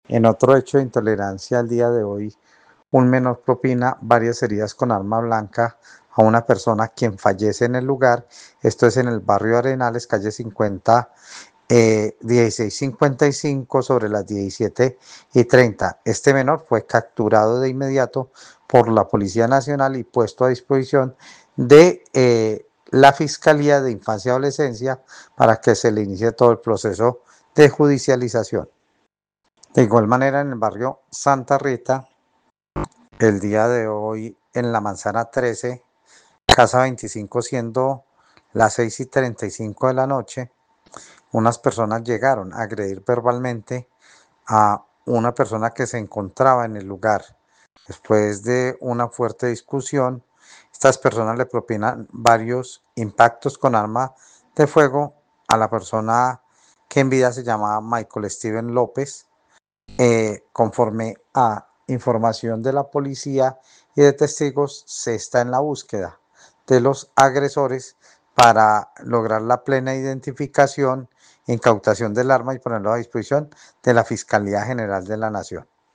Carlos Arturo Ramírez secretario de gobierno de Armenia